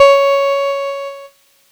Cheese Note 07-C#3.wav